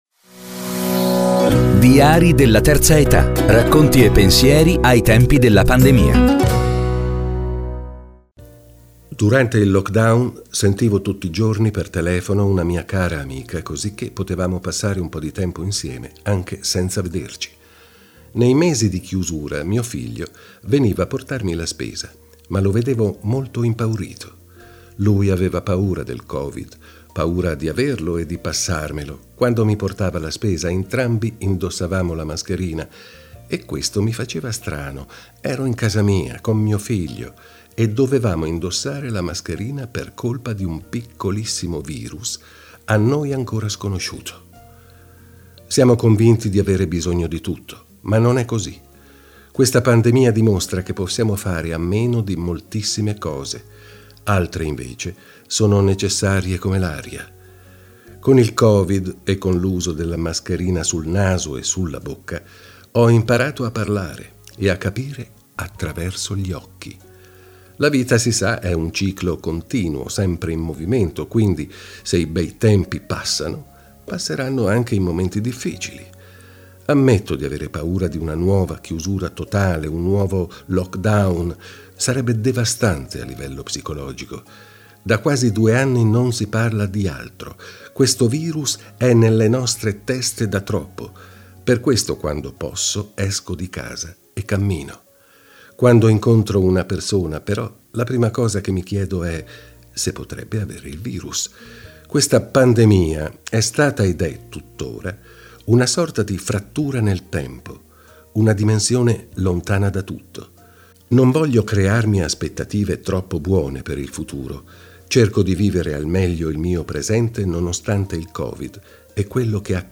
Legge i diari